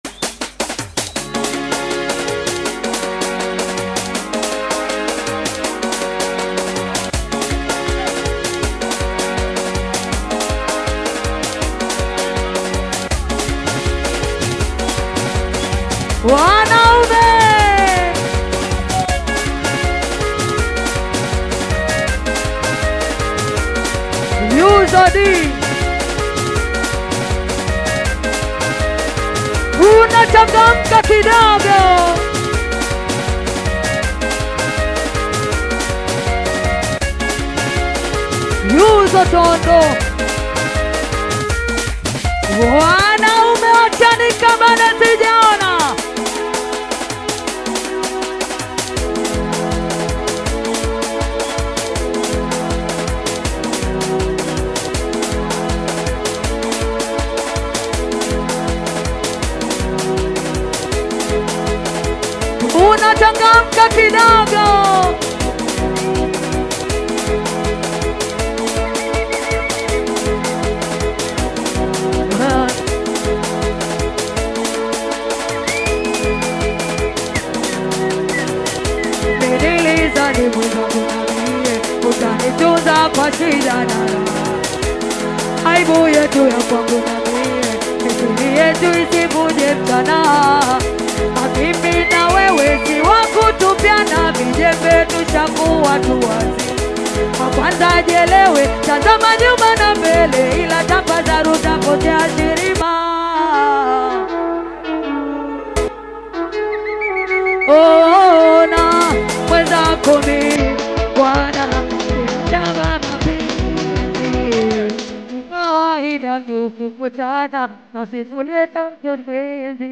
is an energetic and catchy song